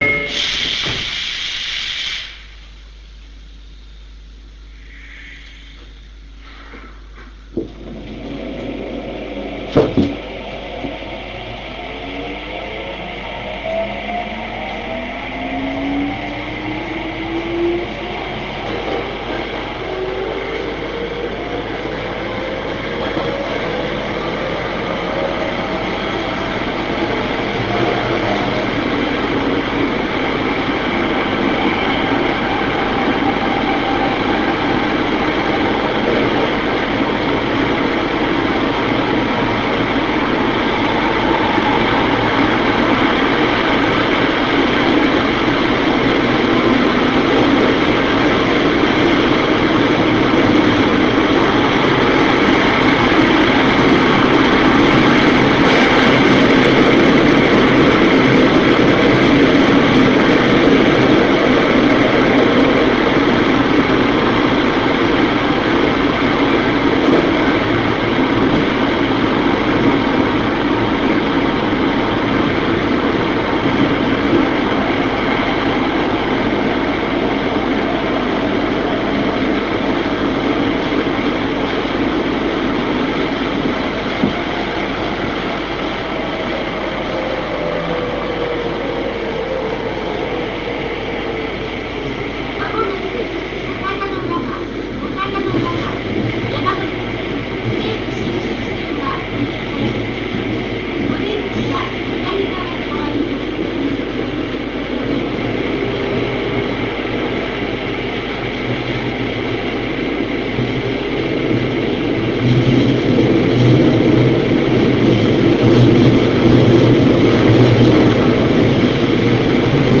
新０５系加速音 西葛西駅 61.1Kb RealAudio形式
試運転中に収録した新０５系の起動・加速音です。